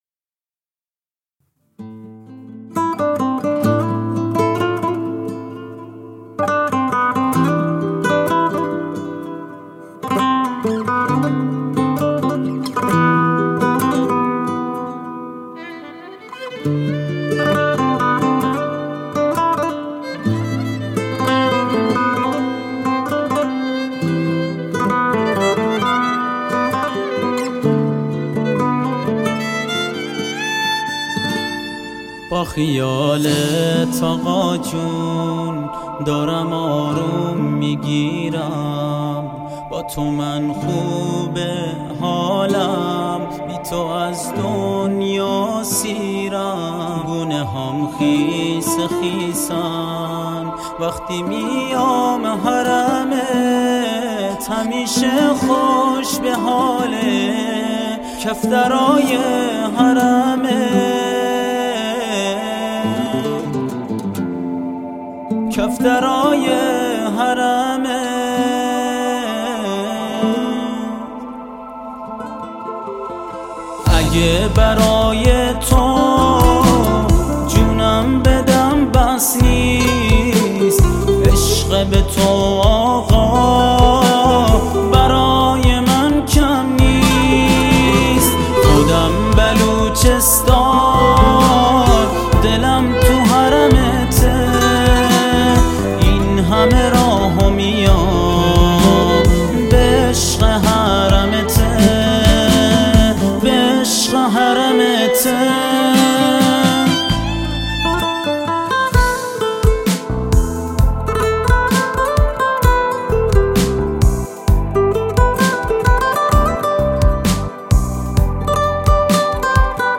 آهنگ بلوچی